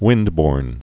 (wĭndbôrn)